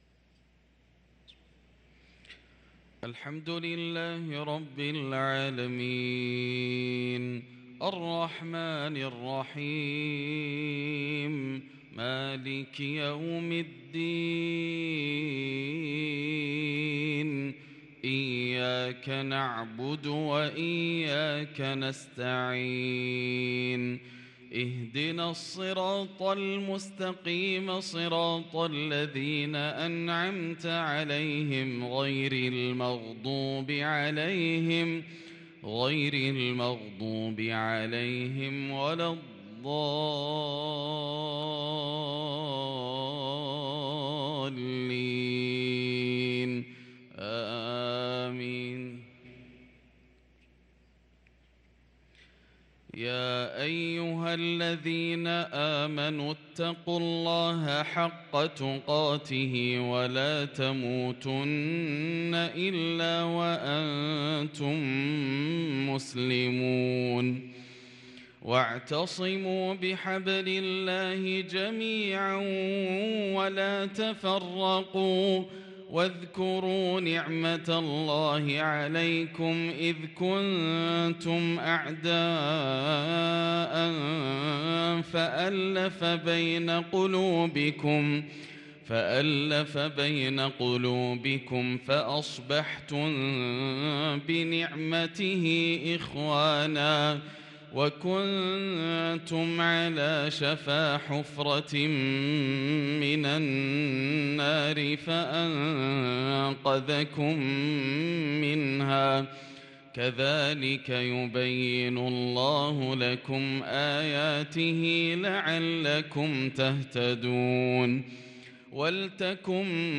صلاة المغرب للقارئ ياسر الدوسري 17 صفر 1444 هـ
تِلَاوَات الْحَرَمَيْن .